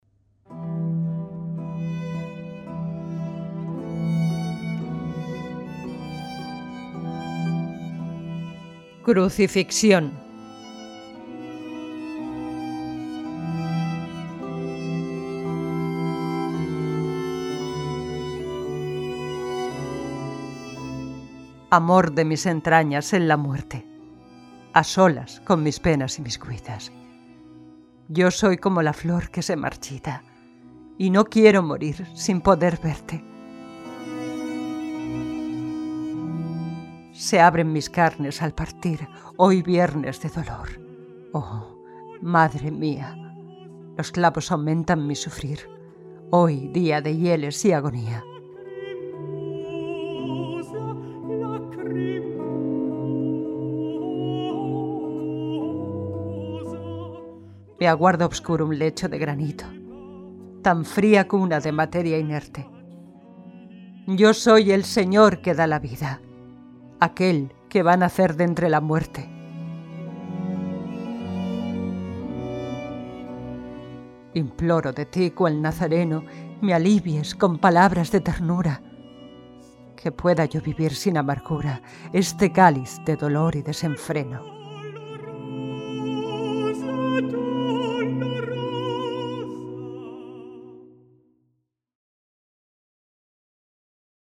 Selección Musical